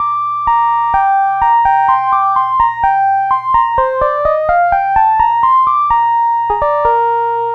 Track 10 - Synth 03.wav